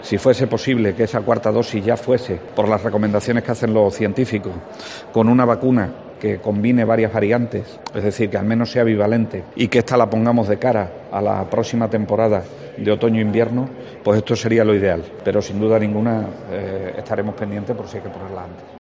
Vergeles ha realizado estas declaraciones momentos antes de asistir, en Mérida, a la entrega de Premios Oacex 2022 a la accesibilidad cognitiva, organizados por Plena Inclusión.
Vergeles, consejero de Sanidad, sobre la 4ª dosis para mayores de 80 años: en otoño y, al menos, bivalente